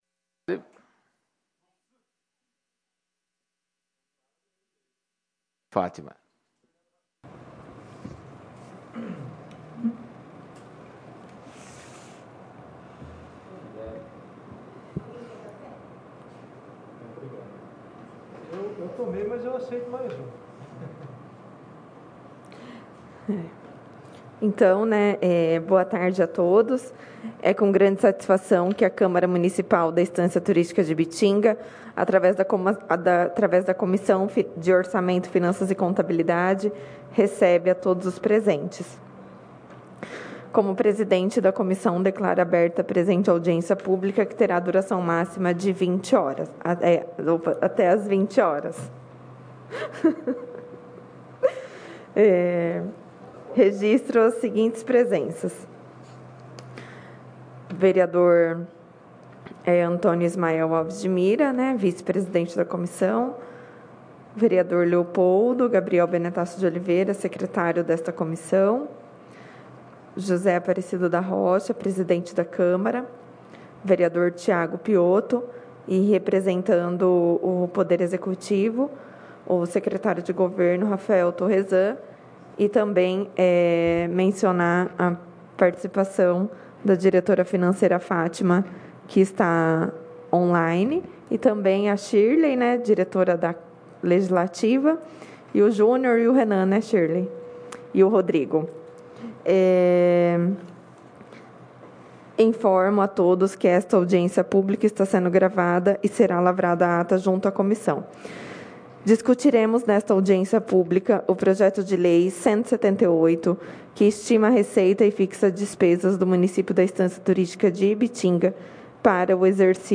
Audiência Pública de 09/11/2020